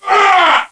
SWORDHIT.mp3